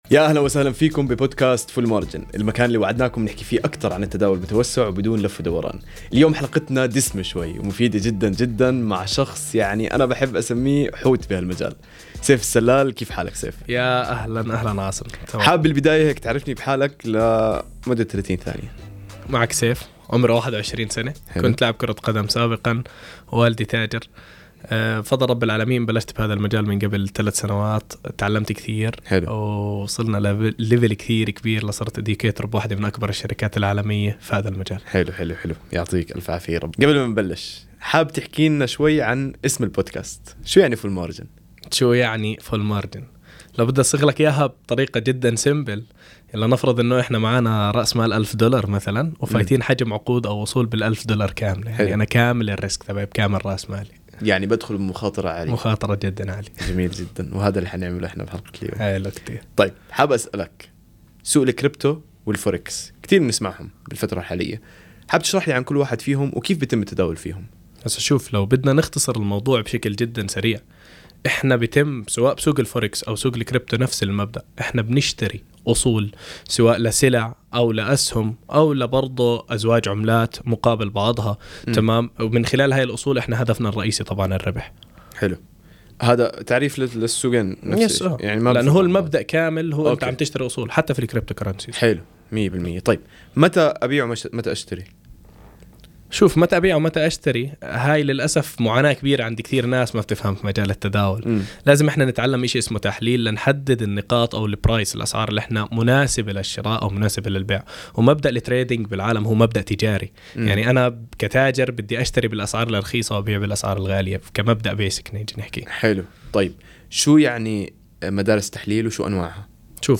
التعريف بالضيف وبداية الحوار.